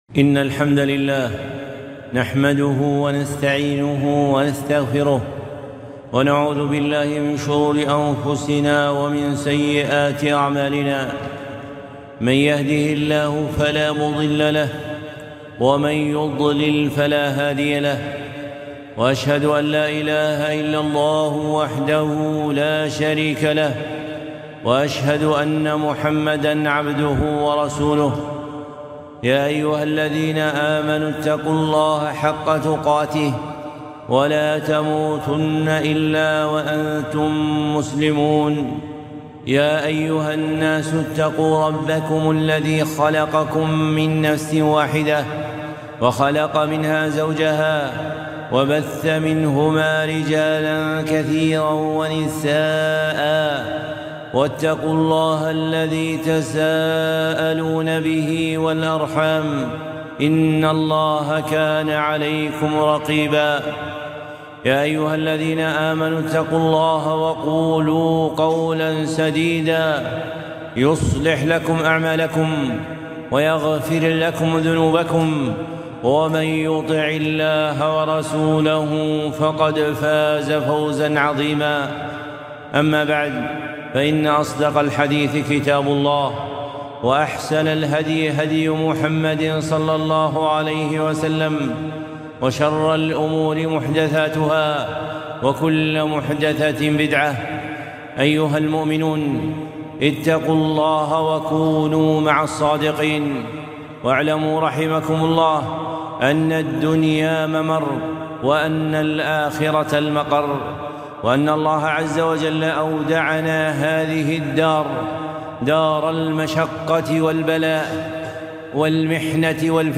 خطبة - المخرج من شدة الدنيا - ٢٣ ذو الحجة ١٤٤٣